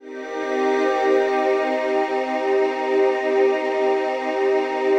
CHRDPAD081-LR.wav